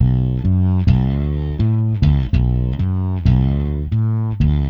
Ala Brzl 1 Fnky Bass-B.wav